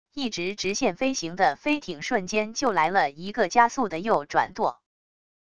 一直直线飞行的飞艇瞬间就来了一个加速的右转舵wav音频